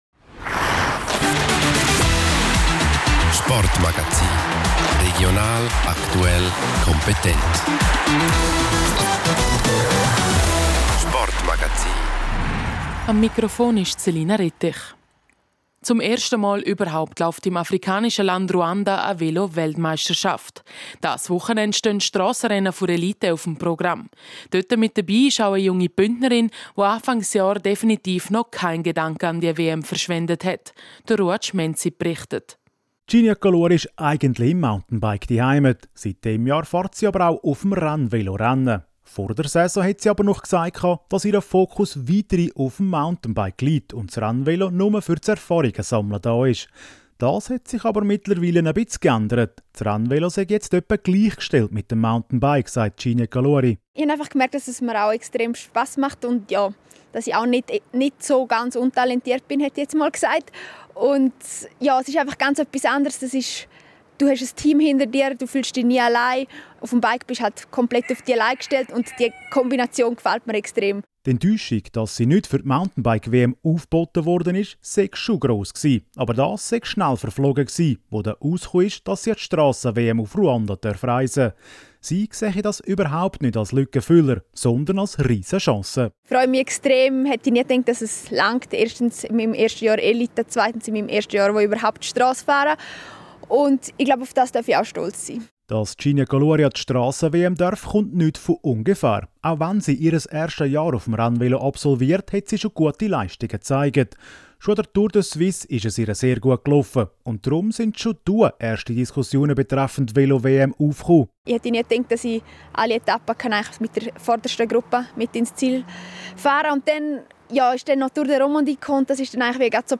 Regionalsport